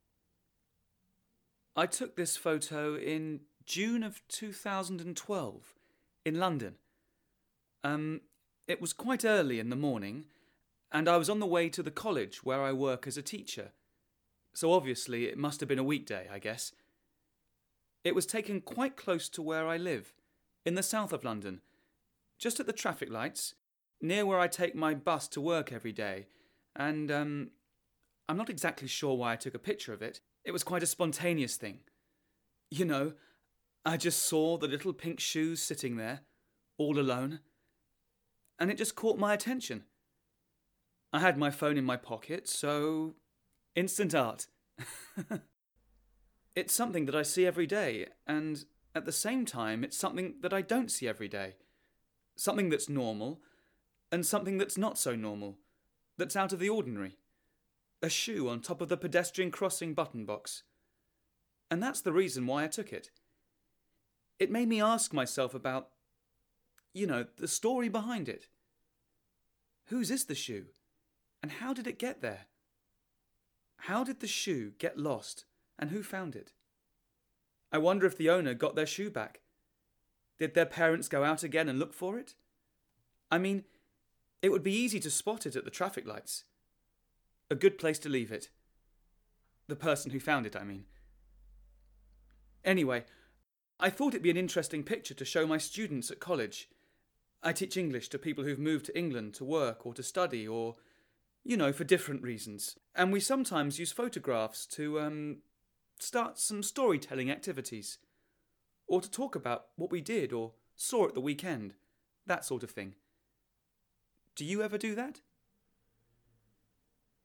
Each pack in this series includes an audio recording of the photographer talking about their photo, together with a complete downloadable lesson plan on how to exploit the image and the audio.